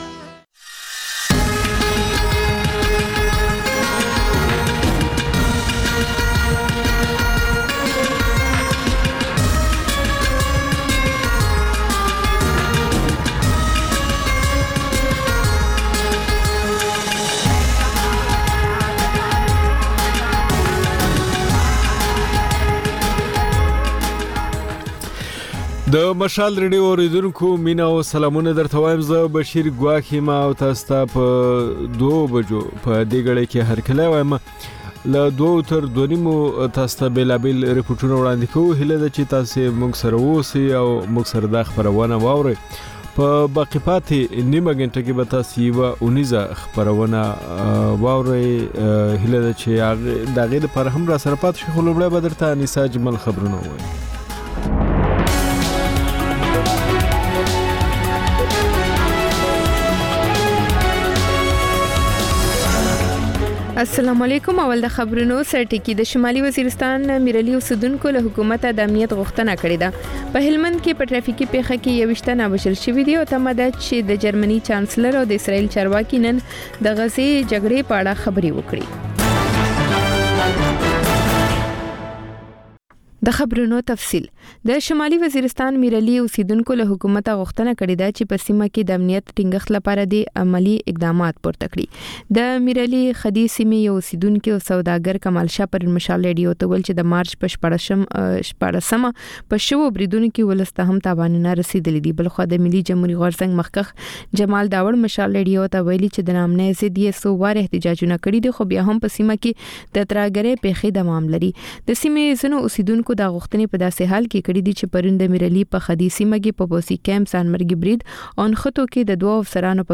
په دې خپرونه کې لومړی خبرونه او بیا ځانګړې خپرونې خپرېږي.